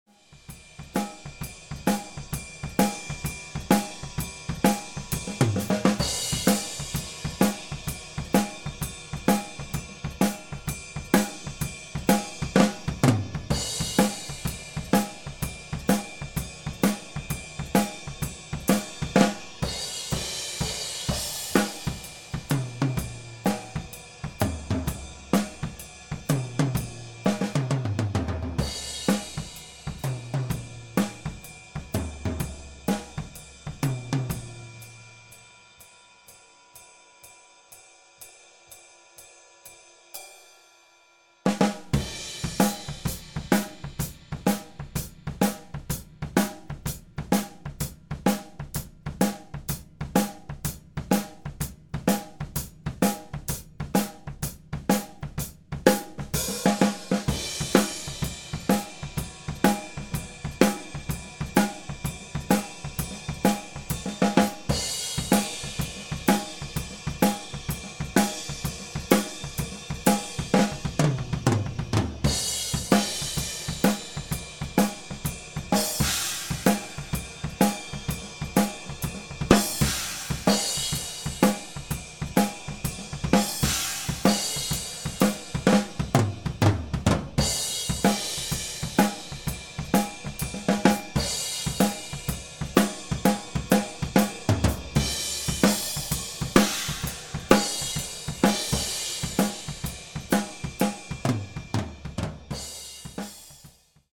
Edit: OHs pur und ohne Schminke:
e664 in ORTF - unbearbeitet ohne EQ, ohne Kompression